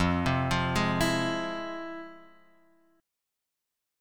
F Minor Major 7th